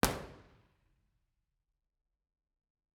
IR_EigenmikeHHF1_processed_Bformat.wav